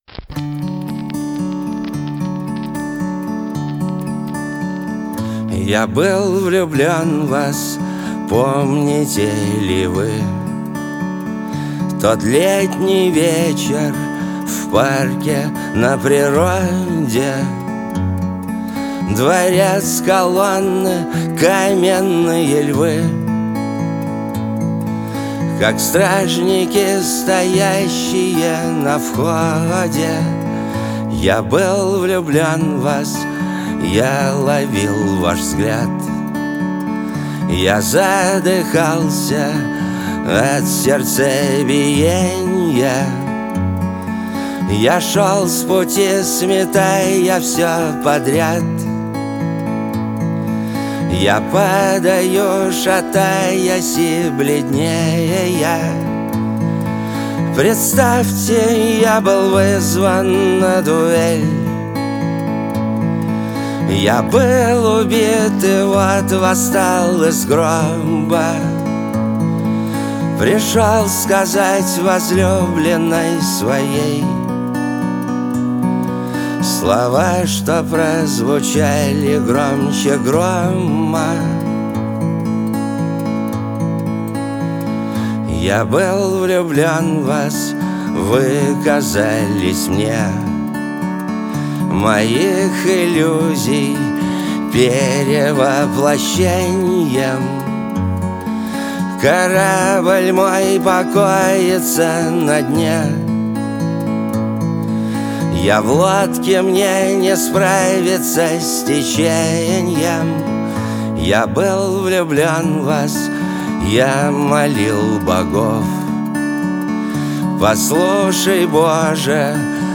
Звучание песни отличается мягкими гитарными рифами